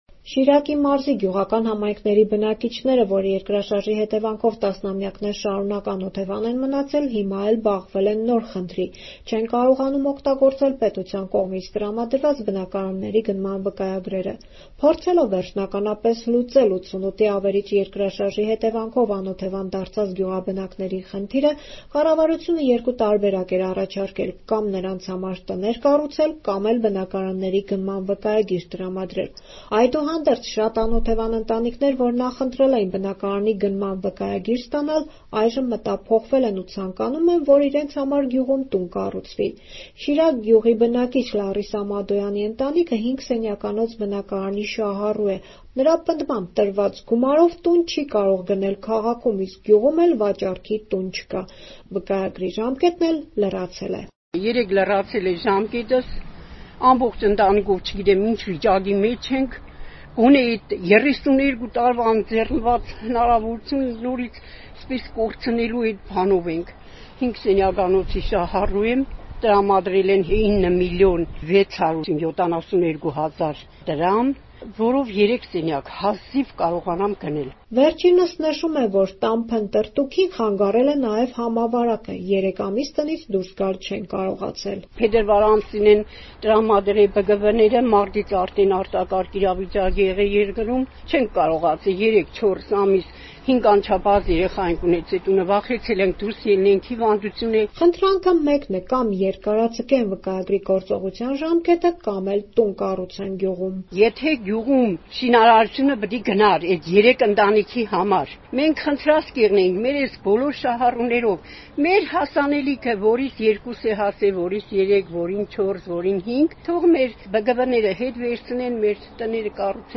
Ռեպորտաժներ